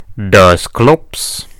infinitefusion-e18/Audio/SE/Cries/DUSCLOPS.mp3 at releases-April